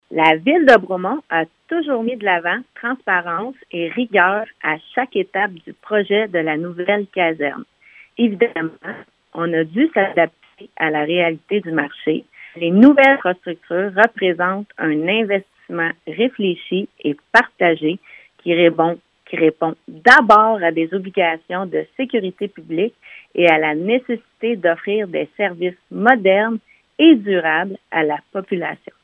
Questionnée sur cette demande, la mairesse de Bromont, Tatiana Contreras, avait ceci à mentionner.